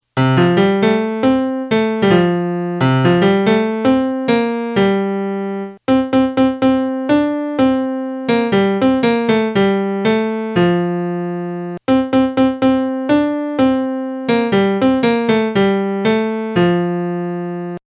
io-parto-per-lamerica-melody.mp3